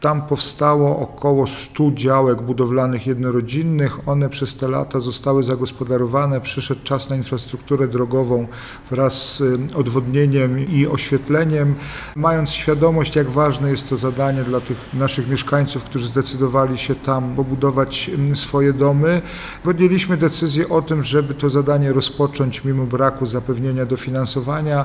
– Rozpoczynamy tę inwestycję pomimo braku jakiegokolwiek dofinansowania. Uważamy bowiem, że trzeba zapewnić komfort tym mieszkańcom, którzy postanowili wybudować tam swoje domy – powiedział prezydent Jacek Milewski: